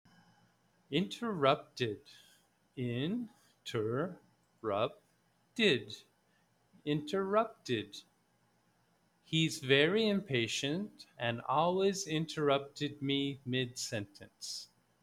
Read the word, study the definition, and listen to how the word is pronounced. Then, listen to how it is used in the sample sentences.